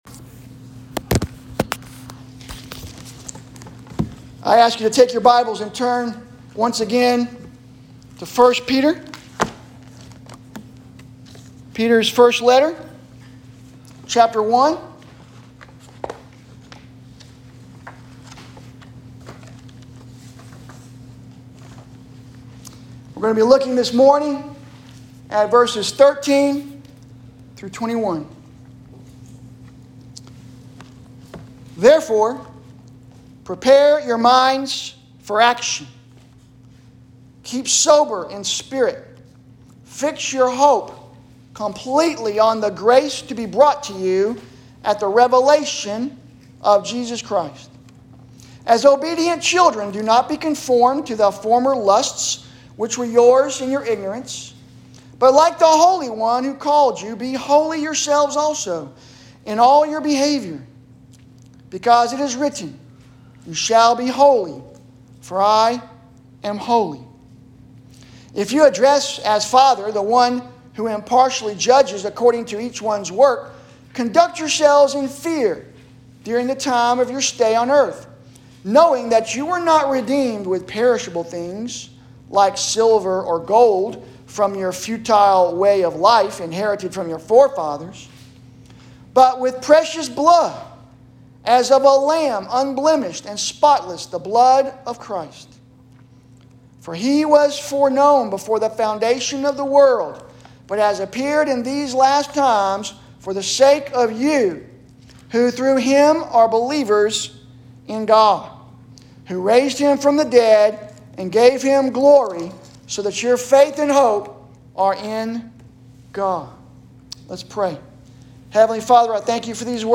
Sermons – First Baptist Church